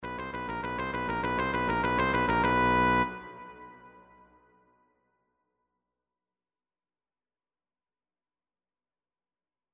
Question 5: Is this a crescendo or diminuendo?
dynamics.mp3